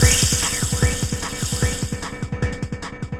Percussion 21.wav